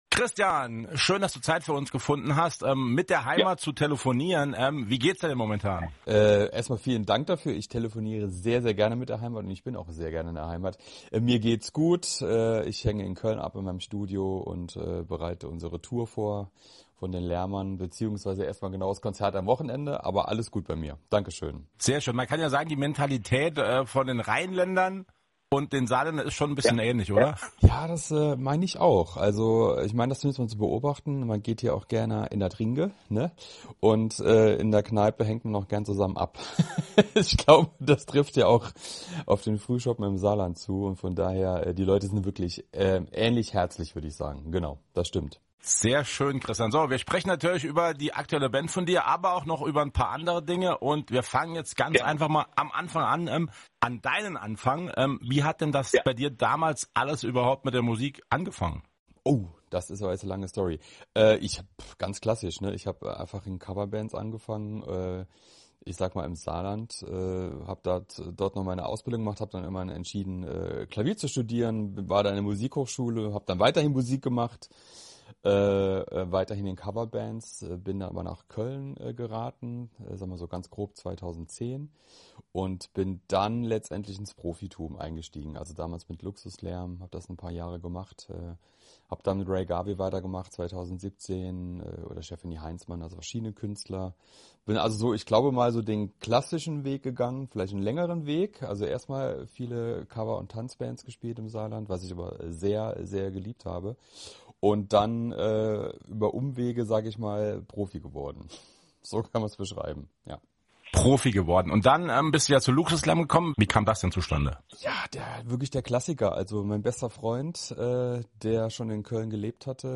Ein ehrliches Gespräch über Musik, Leidenschaft und Neuanfang!